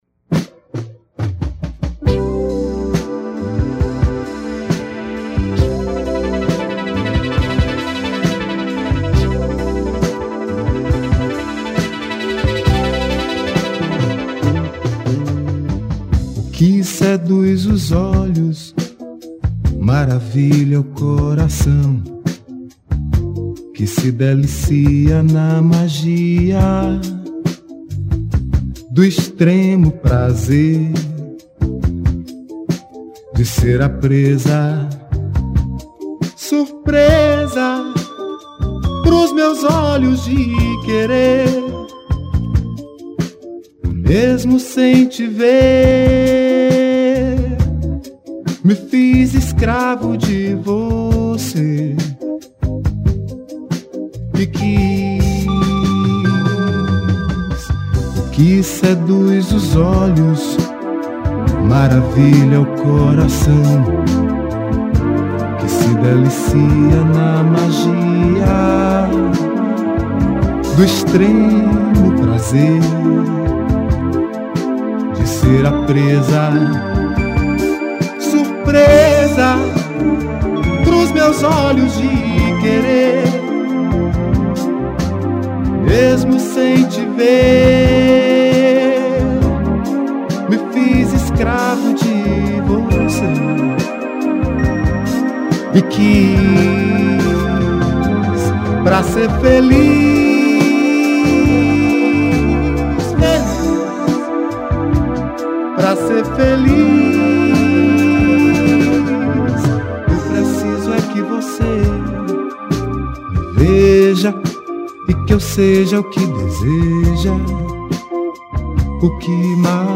15   03:21:00   Faixa:     Mpb